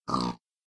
sounds / mob / pig / say2.ogg